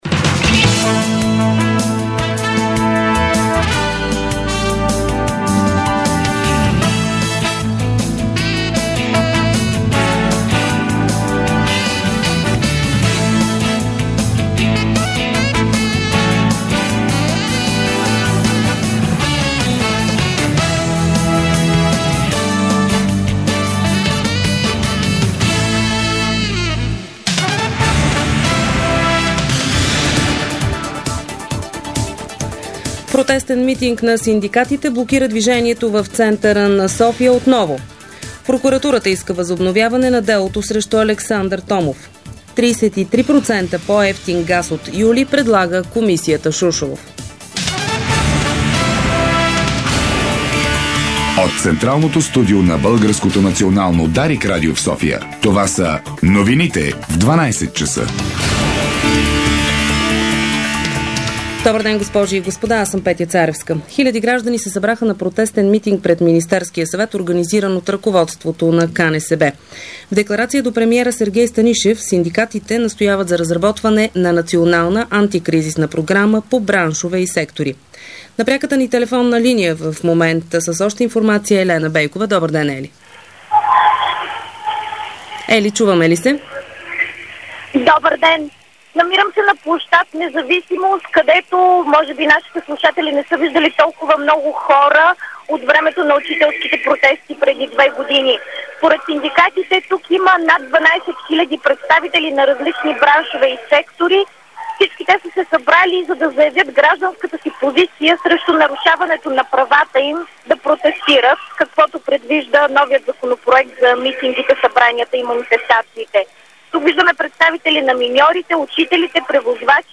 Обедна информационна емисия - 16.06.2009